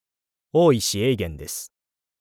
ボイスサンプルはこちら↓